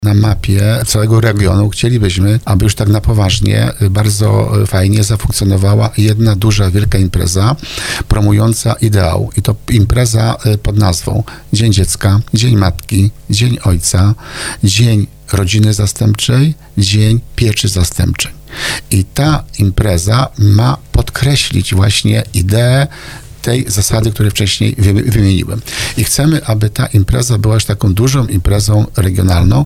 Wójt gminy Tarnów Grzegorz Kozioł przyznał na antenie Radia RDN Małopolska, że Rodzinny Dzień Dziecka będzie odbywać się cyklicznie.